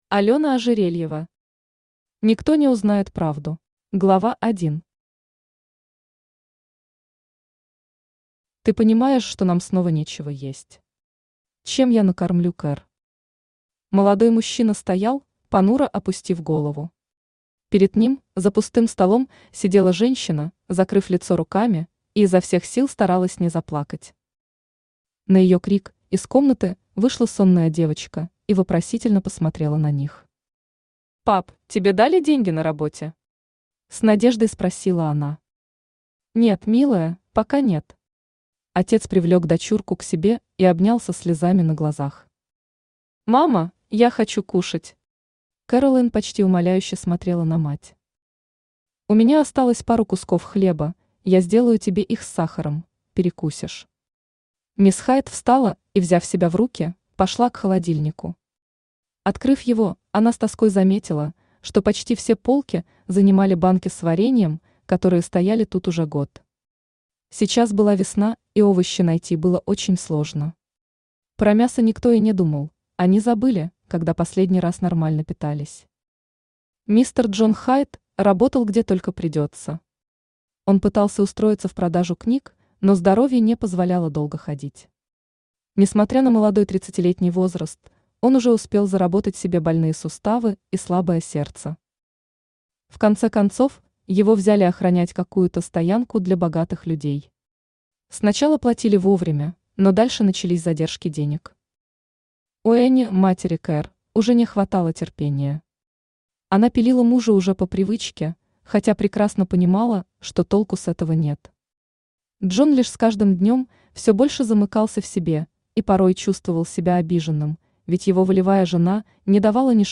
Аудиокнига Никто не узнает правду | Библиотека аудиокниг
Aудиокнига Никто не узнает правду Автор Алёна Игоревна Ожерельева Читает аудиокнигу Авточтец ЛитРес.